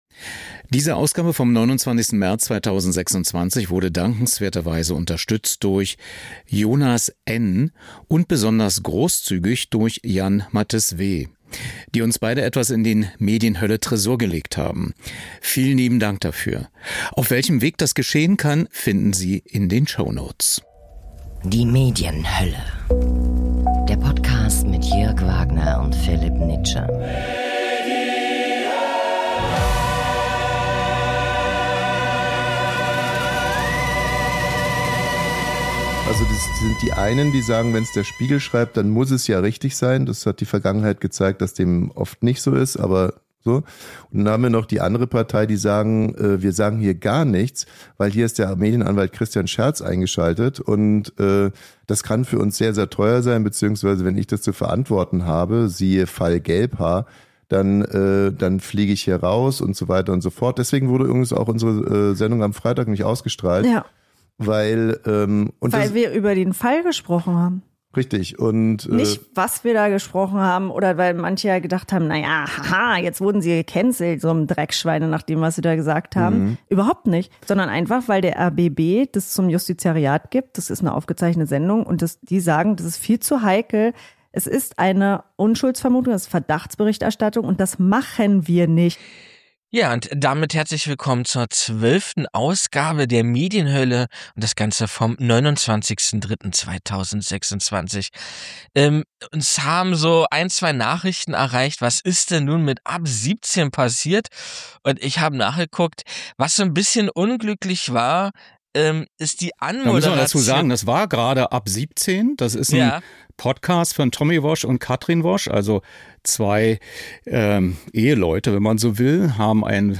Steve Jobs, Apple Co-Gründer, 09.01.2007 * Alexander Kluge, Filmemacher und literarischer Autor (1932-2026)